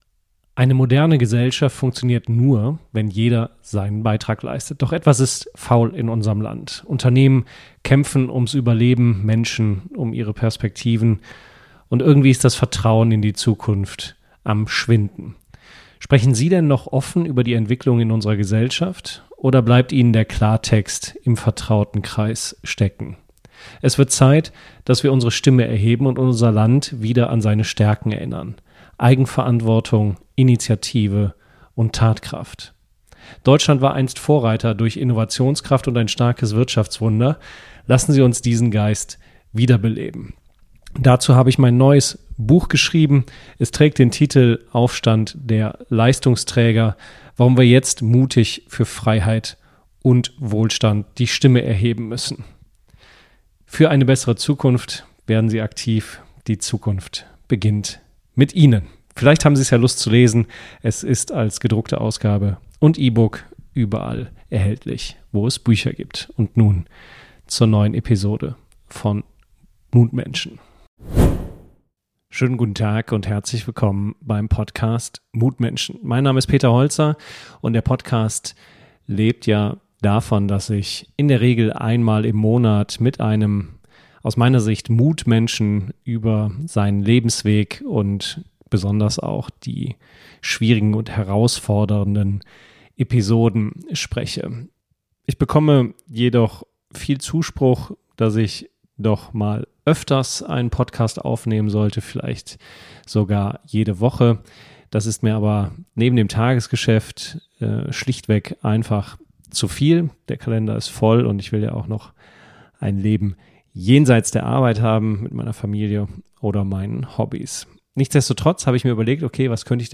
Beschreibung vor 9 Monaten In dieser Solo-Folge von Mutmenschen spreche ich über meinen aktuellen Gastbeitrag im FOCUS. Es geht um eine unbequeme Wahrheit: Viele Führungskräfte haben in der Dauerkrise den Mut verloren – und damit ihre Wirksamkeit. Mut ist heute keine Option mehr, sondern Voraussetzung für gesunde, handlungsfähige Unternehmen.